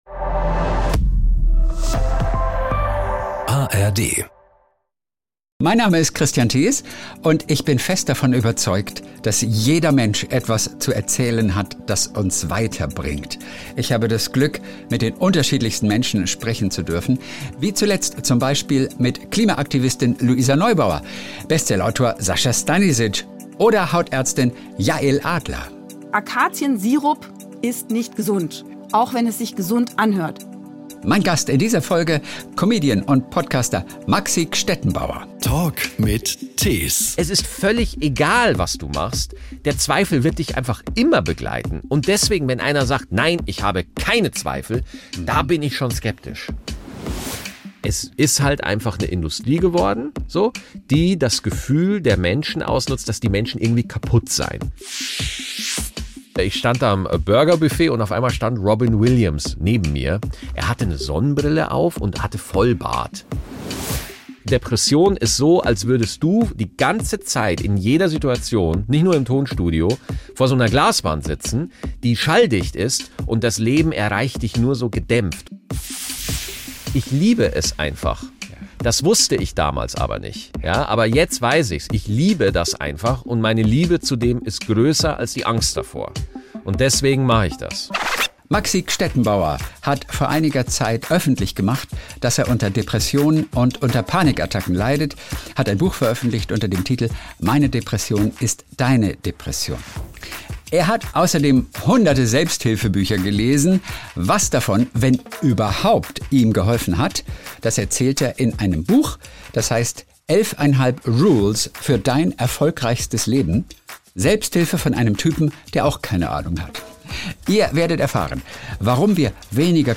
Wie er heute damit umgeht, erzählt er hier.